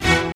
neptunesstringSYNTH2.wav